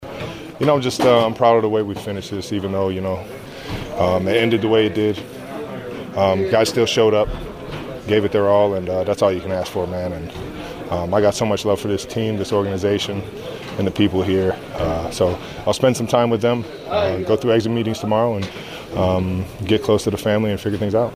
After the game, Travis Kelce talked to the media about his future plans